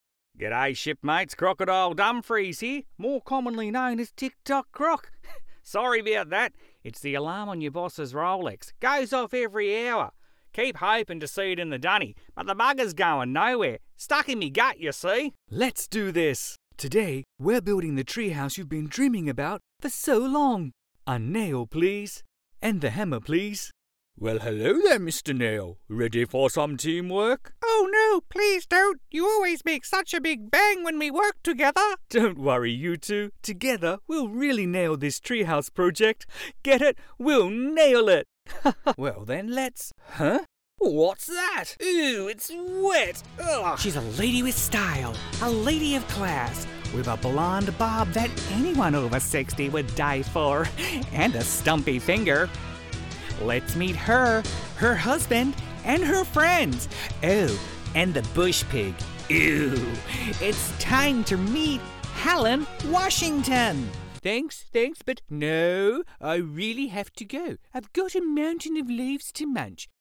Male
My voice is well rounded & I can easily adapt my tone, pitch and pace to get you the voice you're looking for.
A crazy character or the trusted guy next door.
Character / Cartoon
Words that describe my voice are Trusted, Clear, Confident.